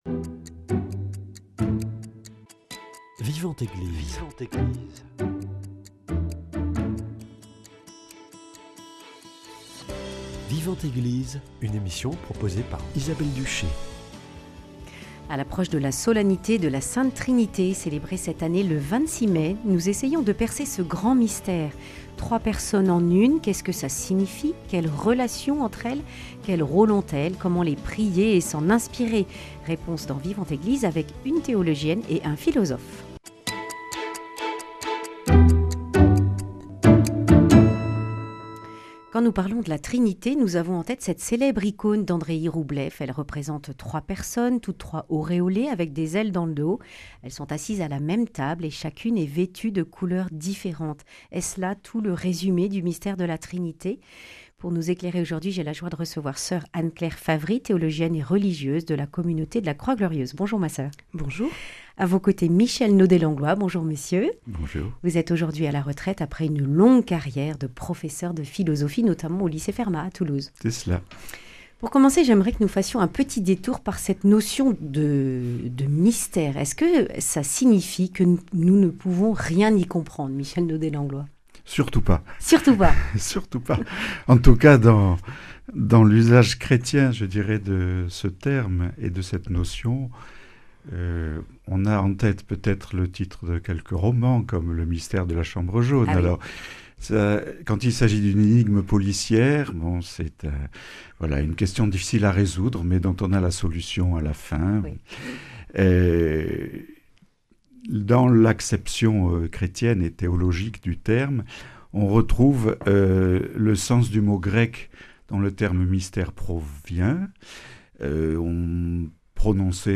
Accueil \ Emissions \ Foi \ Vie de l’Eglise \ Vivante Eglise \ La Trinité, mystère inaccessible ?